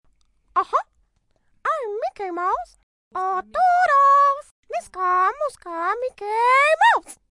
Download Mickey Mouse sound effect for free.